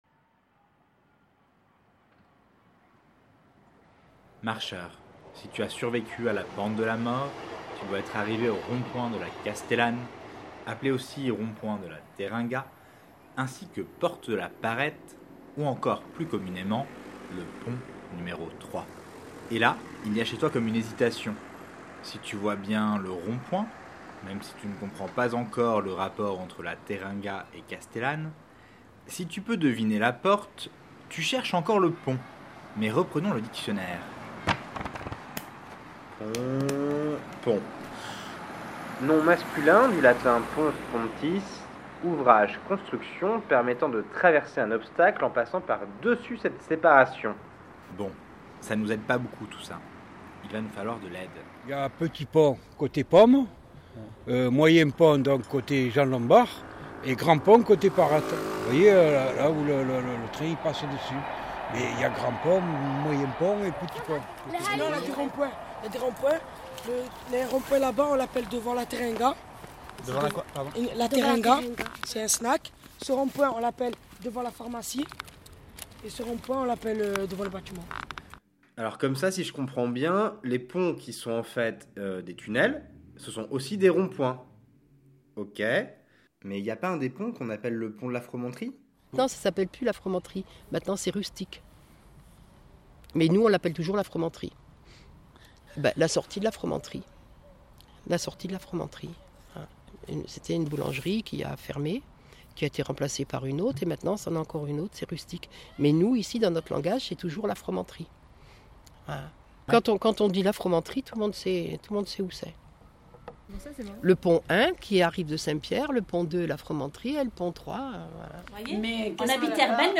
A travers cette balade sonore, de places en places, écoutons les habitants d’Air Bel nous raconter la mémoire de leur quartier.